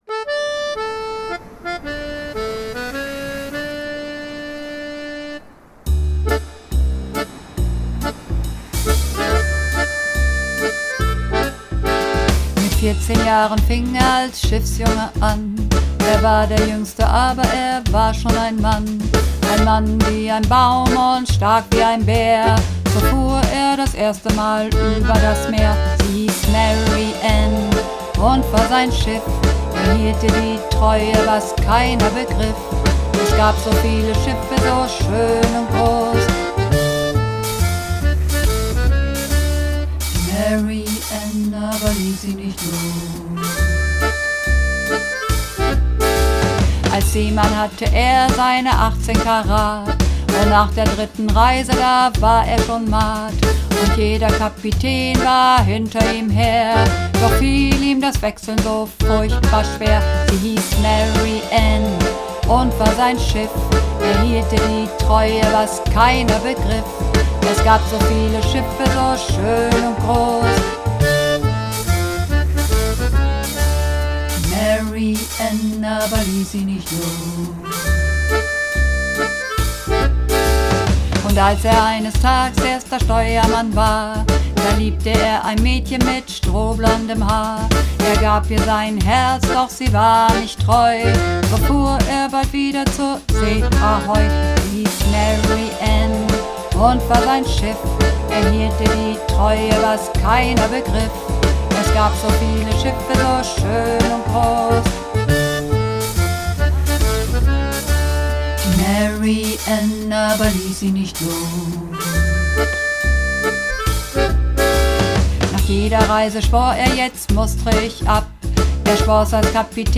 Übungsaufnahmen - Sie hiess Mary Ann
Sie hiess Mary Ann (Männer)